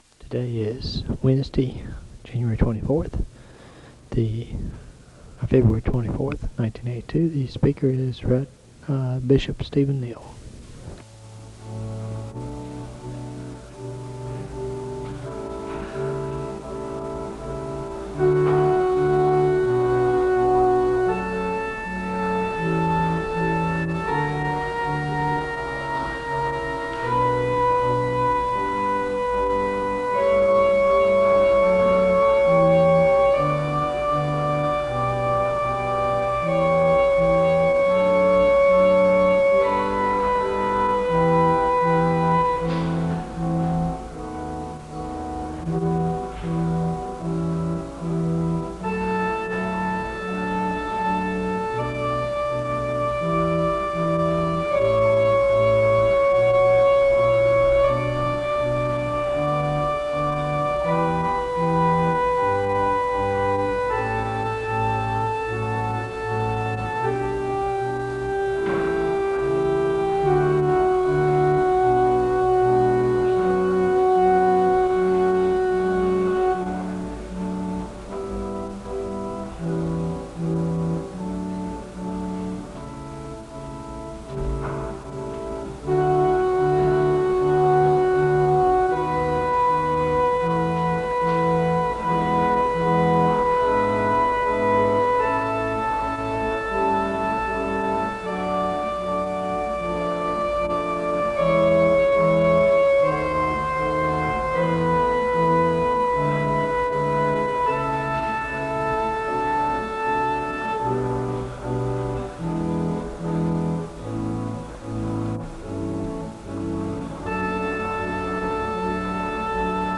The service begins with organ music (00:00-06:59). Stephen Neill gives a word of prayer and leads the audience in a responsive reading of Psalm 51 (07:00-10:16). The choir sings a song of worship (10:17-14:05). Neill speaks about the human’s journey of falling into sin and the second chance and future promise offered in the message of Scripture, and throughout his sermon, he speaks about Dante’s The Divine Comedy as a good picture of the journey towards heaven (14:06-31:37). Neill concludes his sermon with leading the audience in the singing of a hymn and a word of prayer (31:38-34:51).